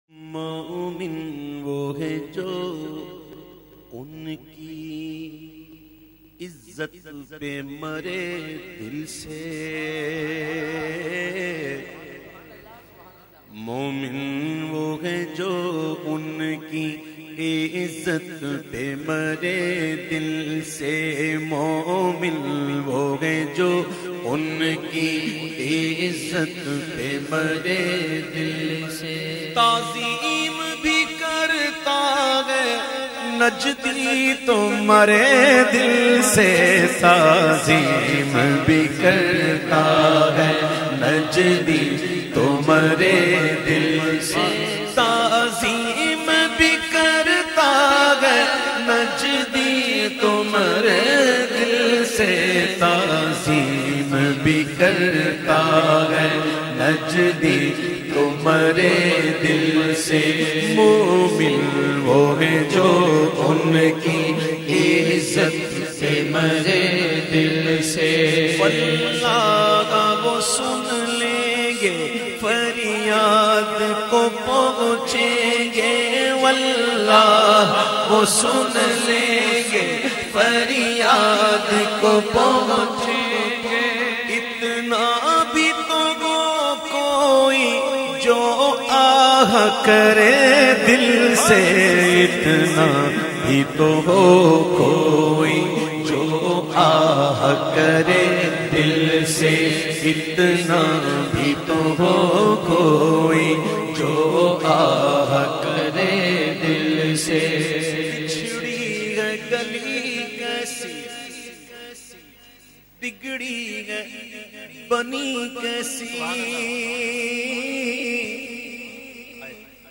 The Naat Sharif Momin Woh Hai recited by famous Naat Khawan of Pakistan Owaise Raza Qadri.